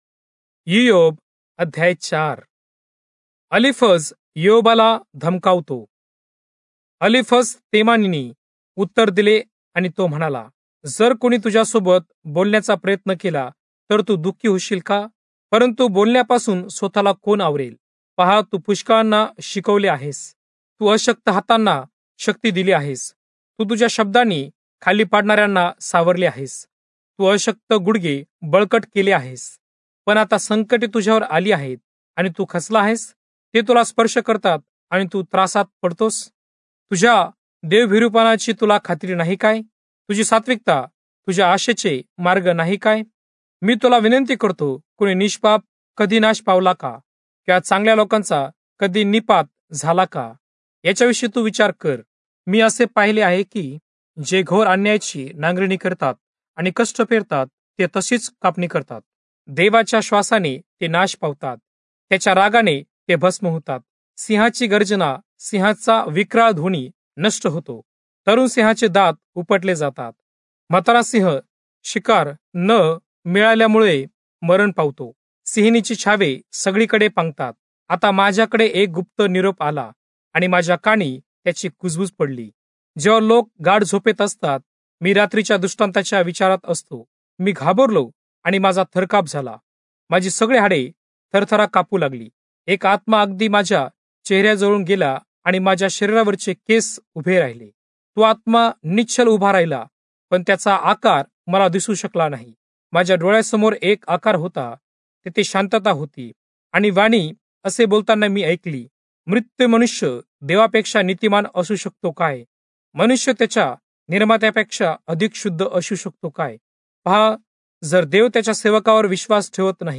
Marathi Audio Bible - Job 8 in Irvmr bible version